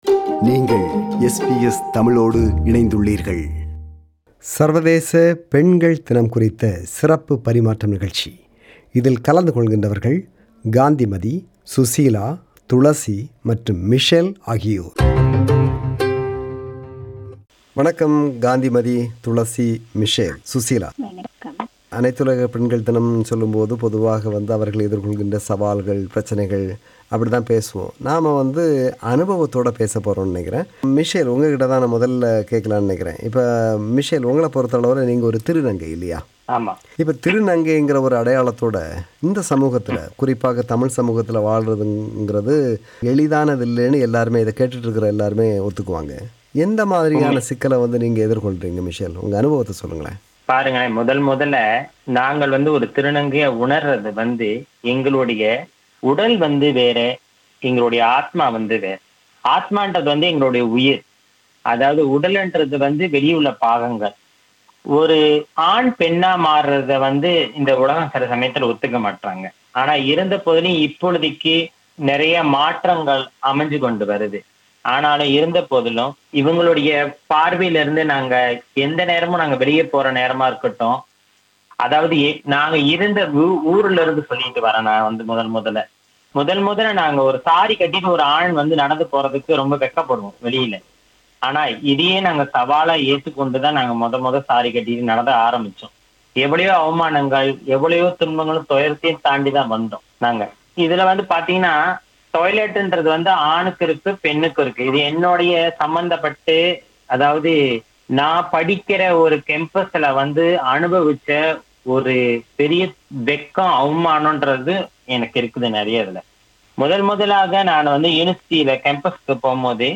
A discussion on International Women’s Day